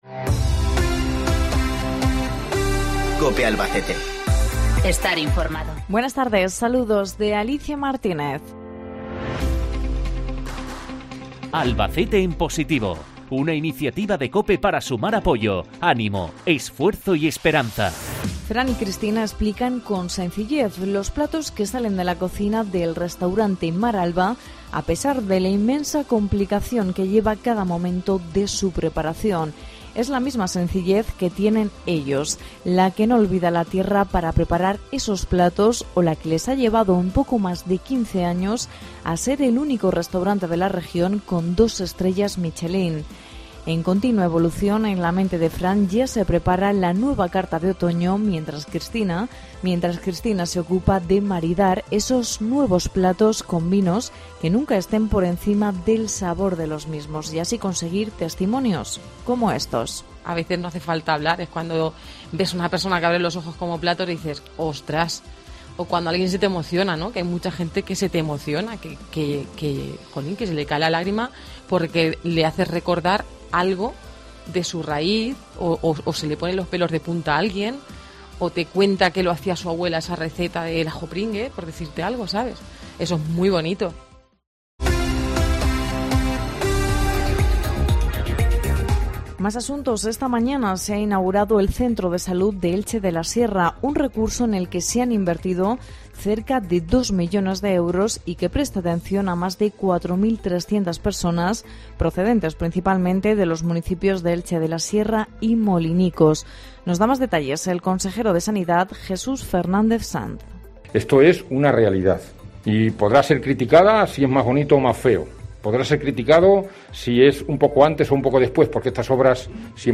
INFORMATIVO LOCAL MEDIODÍA